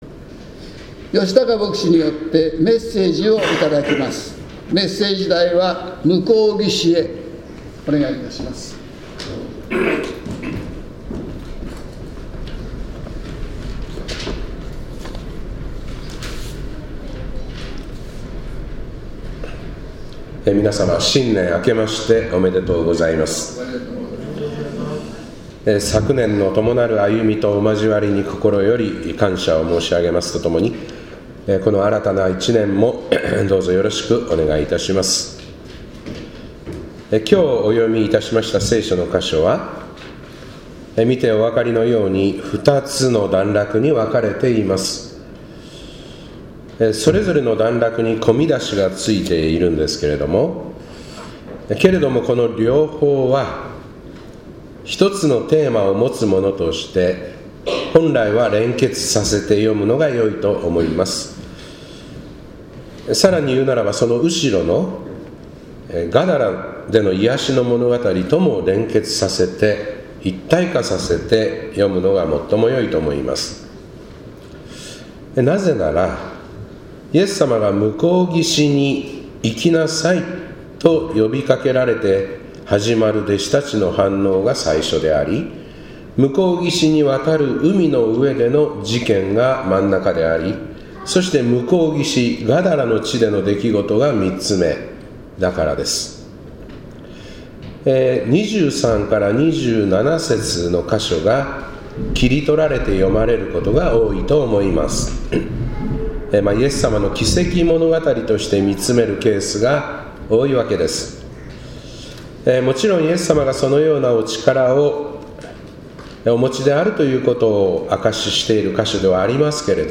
2025年1月1日 元旦礼拝「向こう岸へ」